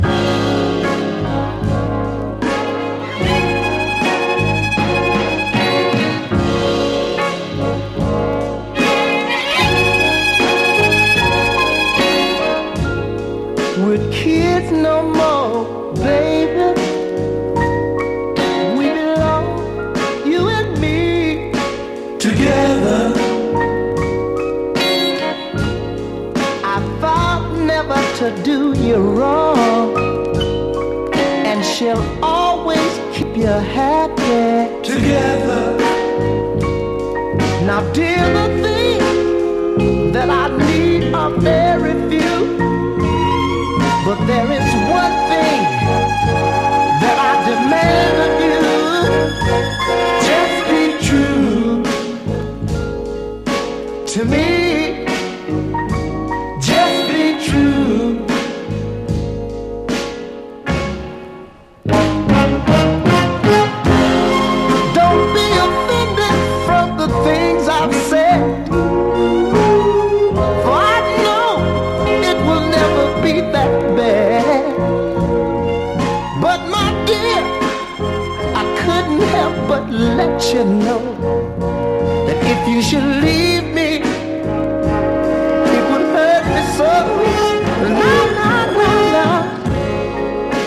黄金のシカゴ・ノーザンソウル・クラシック！